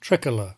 In Britain, on the other hand, the prefix has the vowel of trick, and the middle syllable is weak, as in trilogy:
(Merriam-Webster Learner’s Dictionary: /ˈtraɪˌkʌlɚ/, Brit /ˈtrɪkələ/)
tricolor_br.mp3